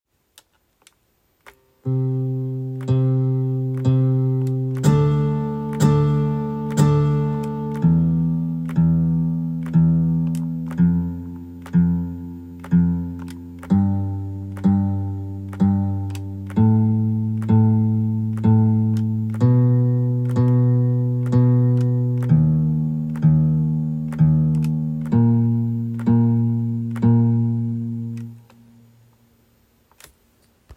MacBook ProへUSB MIDI接続して音色を試していたところ、アコギの音色でDのコードを鳴らす時だけパッド6の音がオクターブ違いの音と混ざっているように聞こえました。
使用環境： MacBook Pro (2020、M1) macOS Sonoma 14.4.1 GarageBand 10.4.6 Steinberg UR22C miditech USB MINI HOST 追記 パッド６の音をボタン１〜９について三回ずつ鳴らした音を添付しました。